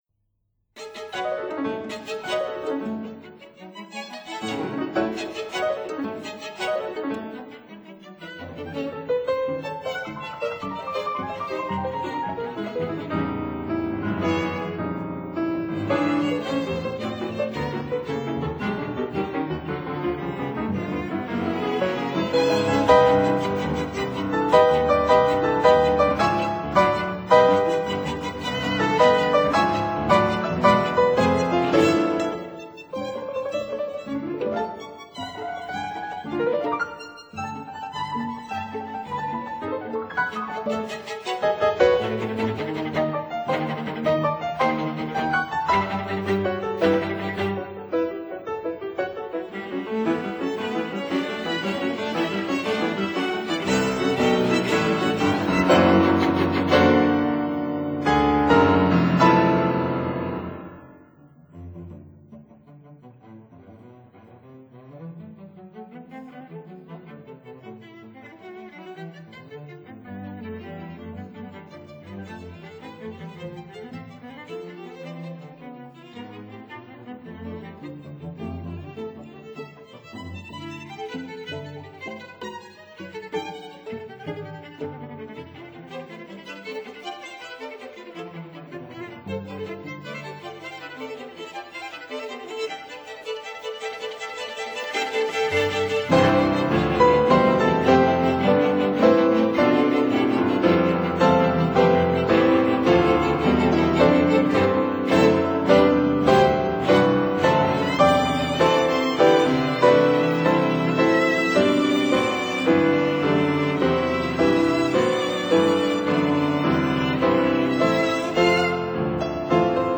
piano
violin
viola
cello